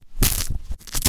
描述：魔术师被摩擦的操纵记录